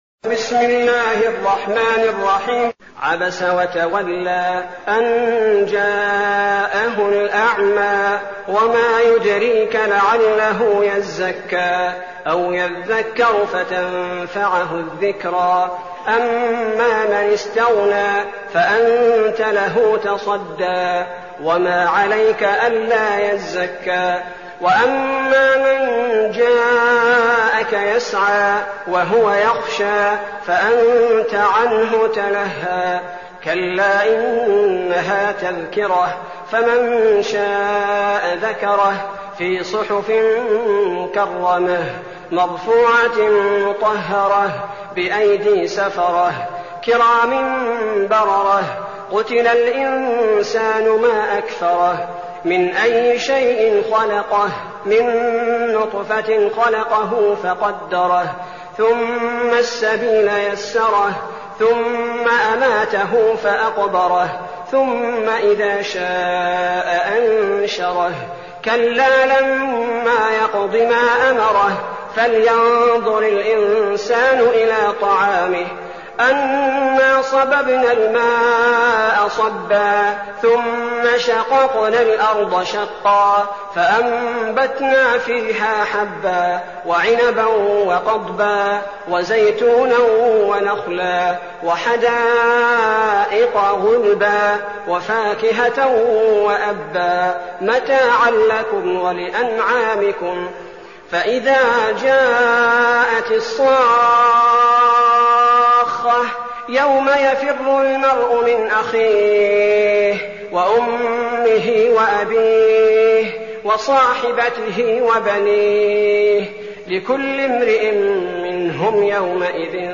المكان: المسجد النبوي الشيخ: فضيلة الشيخ عبدالباري الثبيتي فضيلة الشيخ عبدالباري الثبيتي عبس